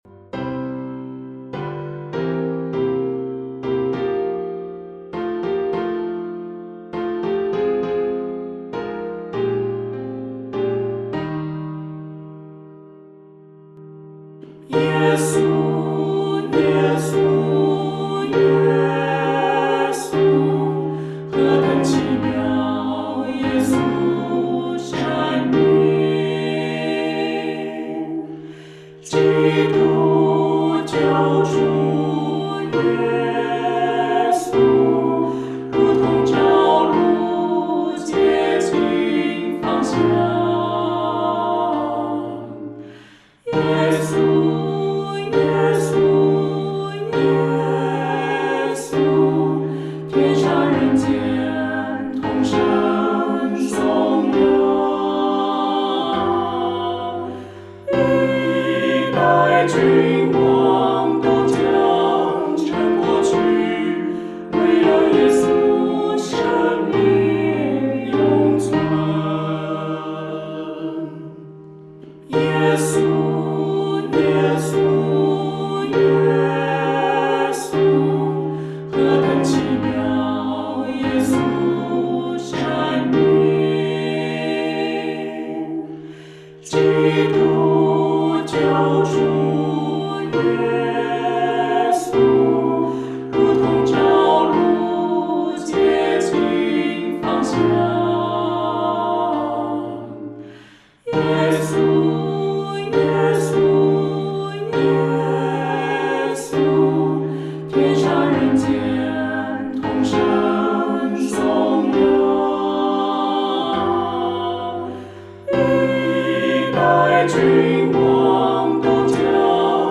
合唱
四声